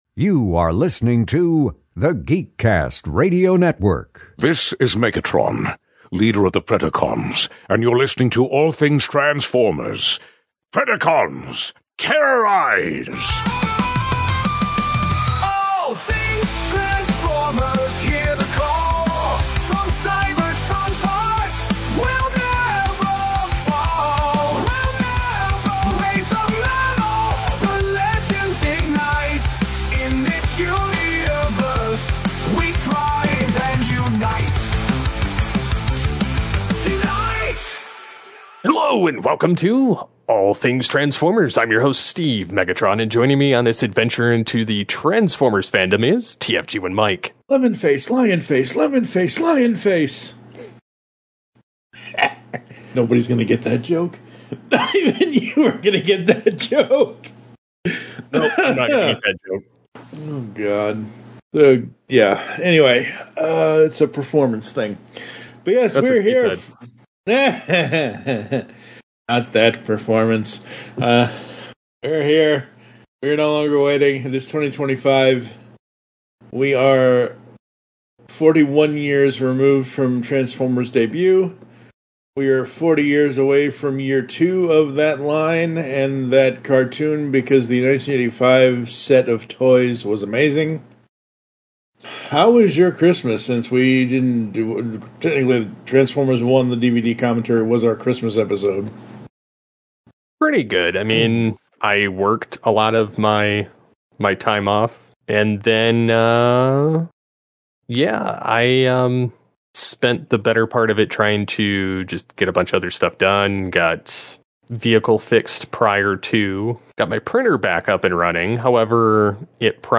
We have Reviews, news, interviews, origins, and insights on shows, comics, movies, toys, and more. From G1 to Beast Wars and beyond, transform and transcend with us in these geeky conversations!